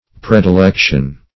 Predilection \Pre`di*lec"tion\, n. [Pref. pre- + L. dilectus, p.